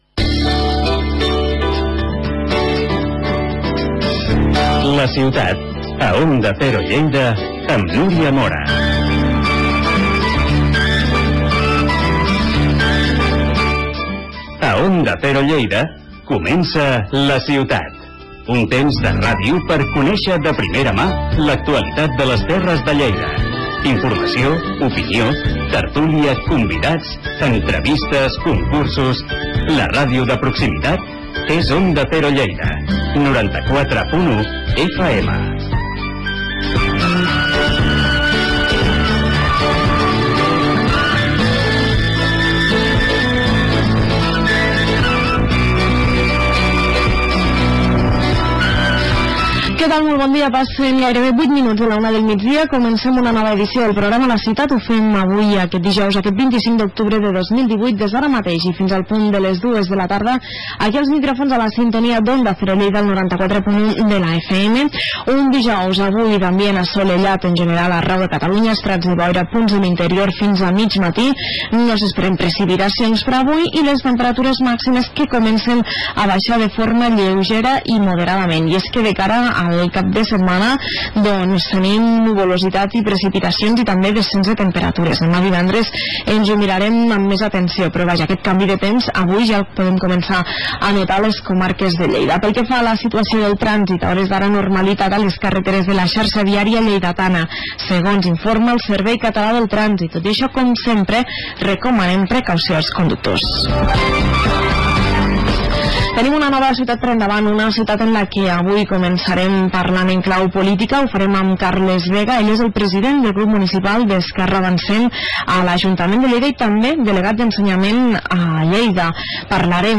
Aquí teniu l'enllaç al programa de ràdio de Onda Cero Lleida: Finestra al món. Avui parlem d'un viatge a la Patagònia. parlem tant de la zona Argentina: Ushuaia, Calafate, el Chaltén i Río Gallegos i de la part xilena: Puerto Natales i Punta Arenas.